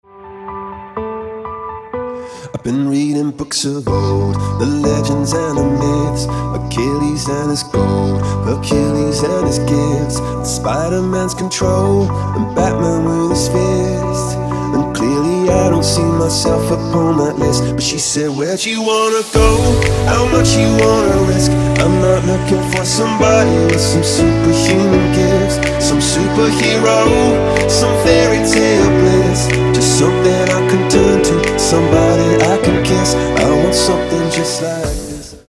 • Качество: 224, Stereo
поп
мужской вокал
dance
Melodic
romantic
vocal